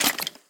Minecraft / mob / skeleton / step1.ogg